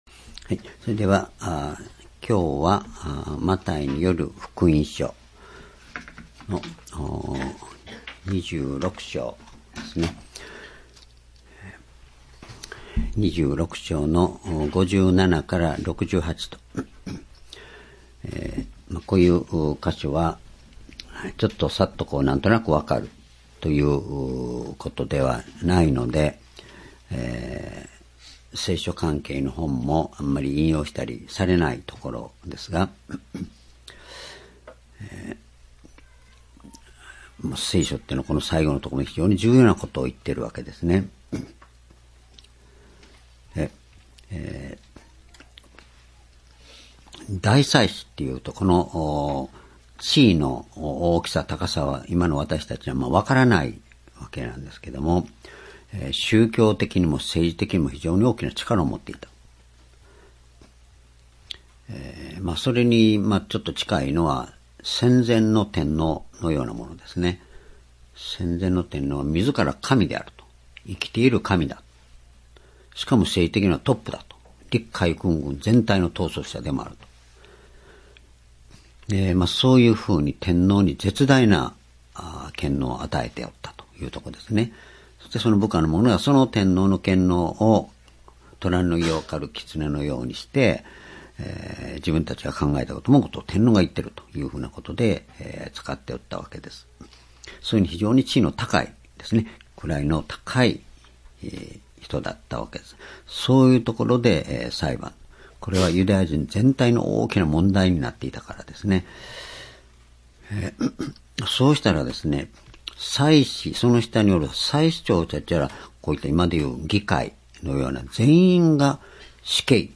｢主は再び来られる｣マタイ福音書26章57節～68節 2020年2月16日 主日